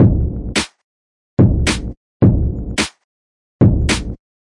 描述：循环鼓108bpm
Tag: 循环 击败